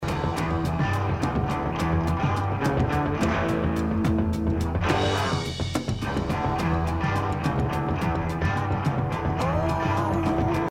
Download Zepp Demo with Super Sizzle Effect: Audio player problem...